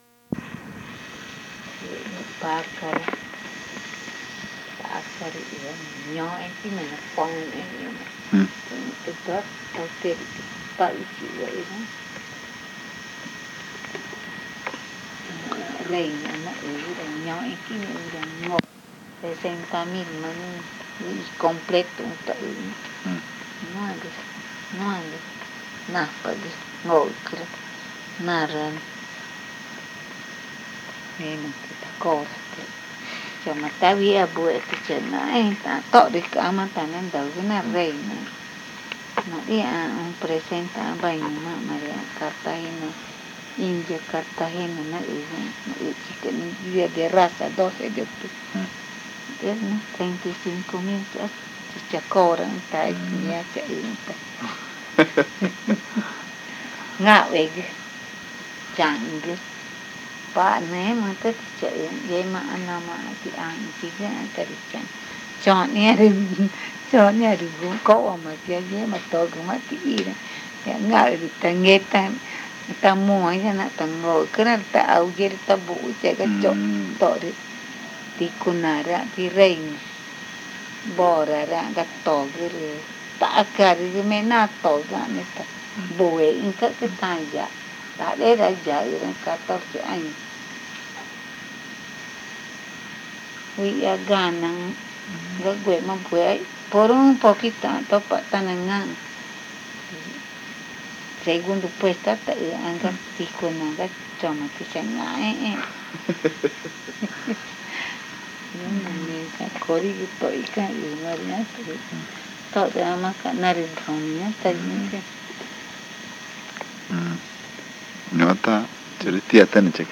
Encuesta léxica y gramatical 24. Ventura n°4
Este casete es el cuarto de una serie de cuatro casetes grabados en Ventura.
El audio contiene solo el lado A.